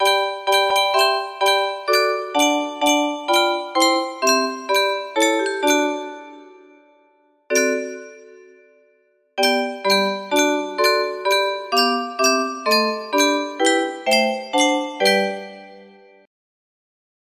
Yunsheng Music Box - SWITPTGB 2566 music box melody
Full range 60